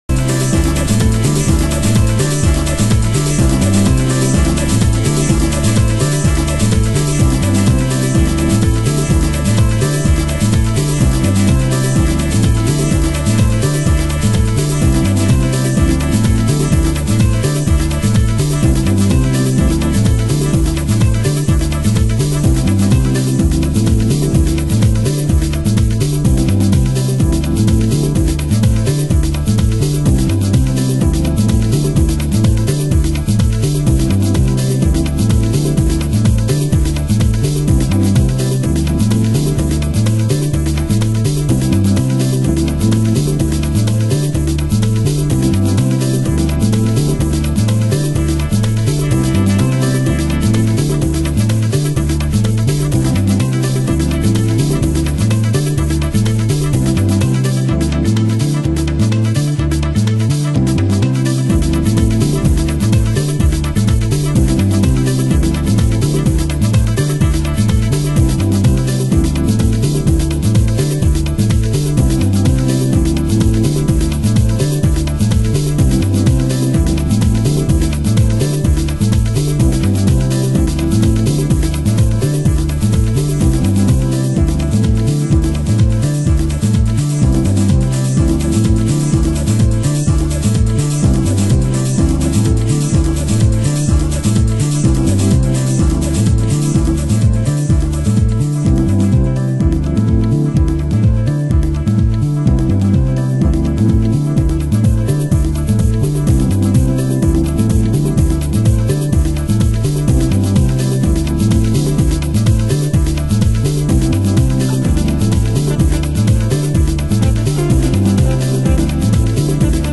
中盤 　　盤質：小傷、少しチリパチノイズ 有　　ジャケ：少しスレ有